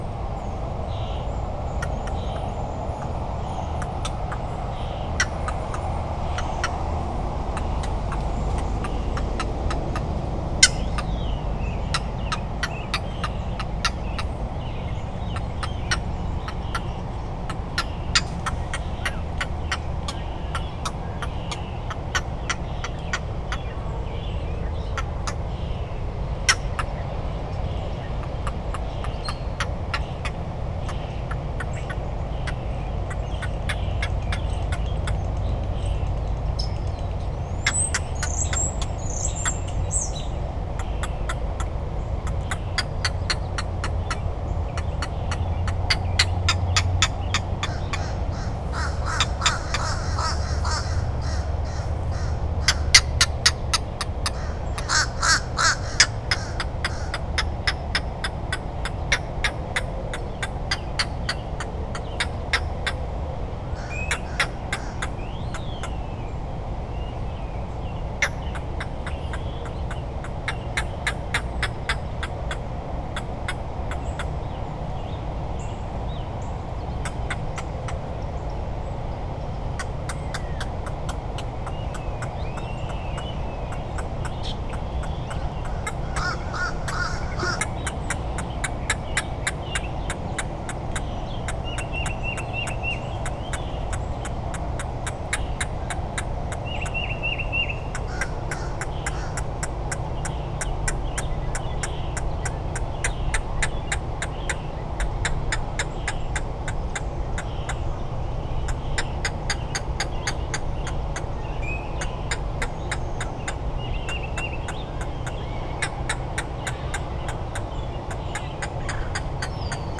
The recording will give you some idea regarding the Lakeside Park soundscape. It features an adult Green Heron returning to its nest, a nest with 3 very young nestlings, and calling repeatedly. Beginning at just under 4 minutes, a Blue Jay alights a few feet from the nest and the parent, in full protective parent mode, goes a little berserk.
green-heron.wav